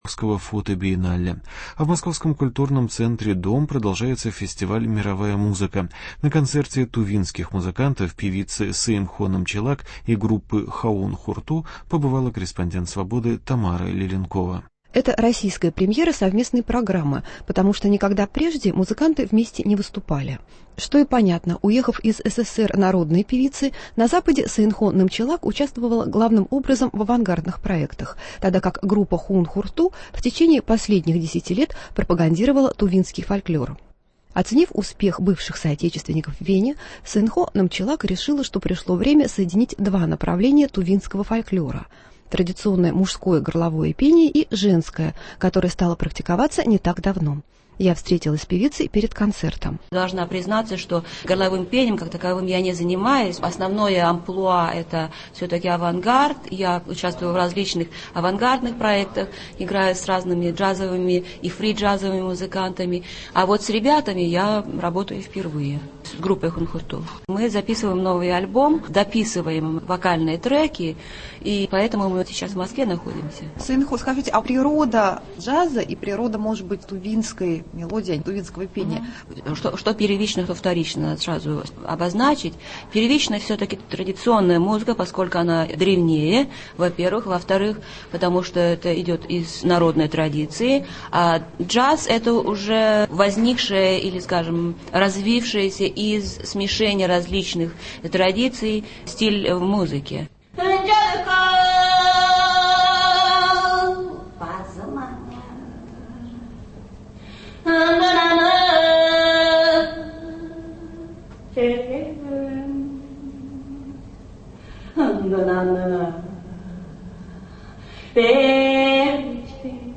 Интервью с тувинскими музыкантами.